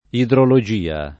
idrologia [ idrolo J& a ]